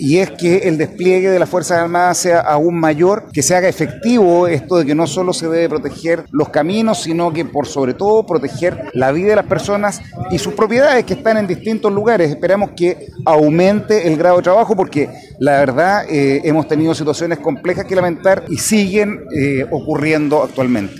Tras el evento castrense, el gobernador Rodrigo Díaz señaló que debe aumentar “el grado de trabajo” de las Fuerzas Armadas en la denominada Macrozona Sur.